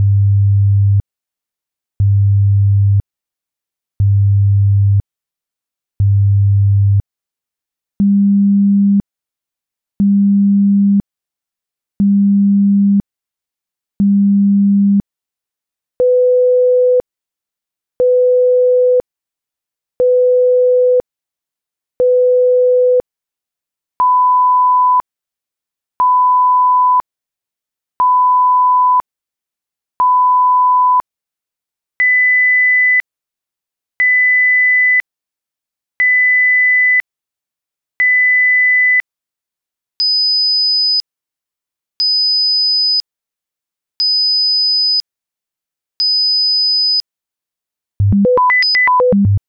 Atlas - STest1-Pitch-Left-100,200,500,1000,2000,5000.flac